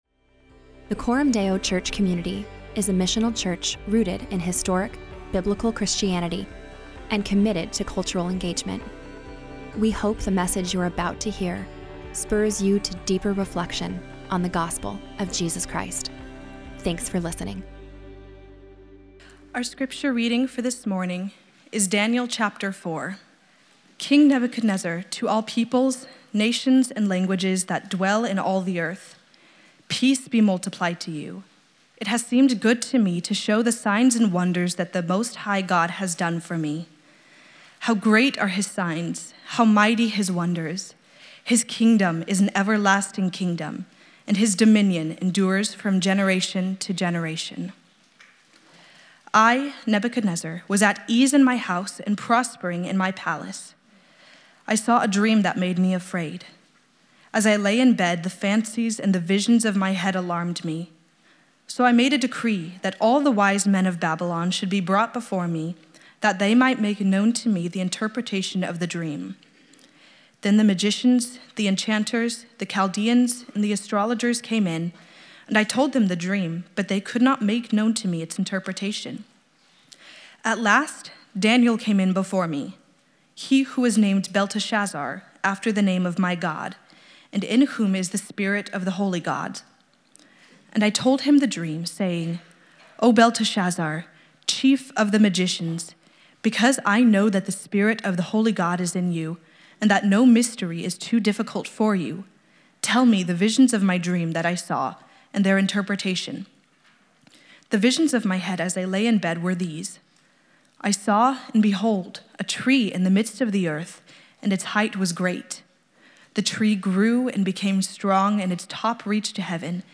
Weekly sermons from Coram Deo Church in Omaha, NE.